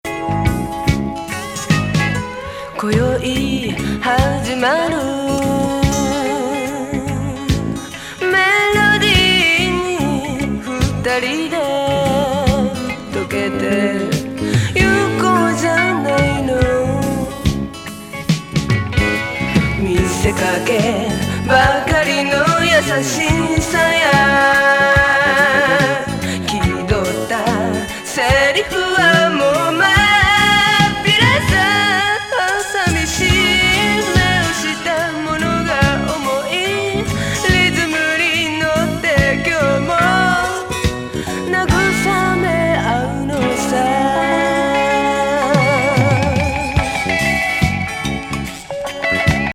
スムース&メロウ・レゲー!